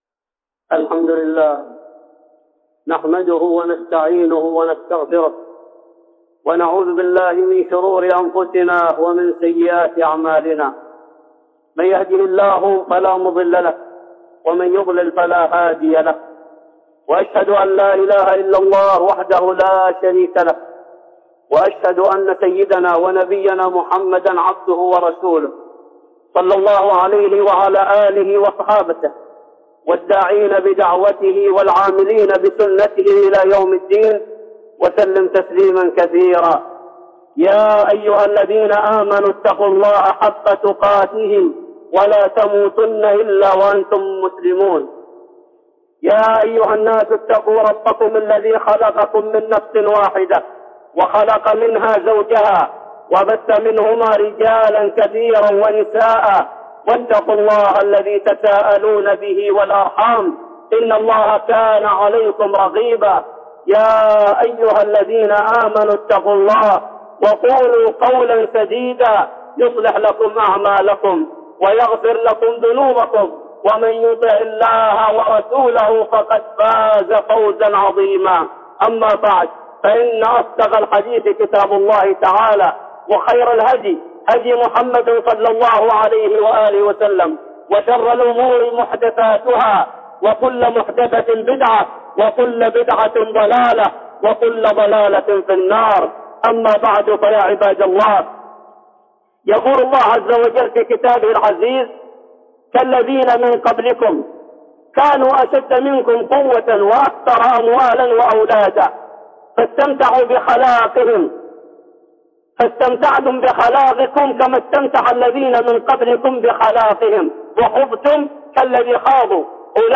(خطبة جمعة) التحذير من القتال بين القبائل وأثره على الأخوة الإسلامية 2